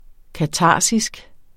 Udtale [ kaˈtɑˀsisg ]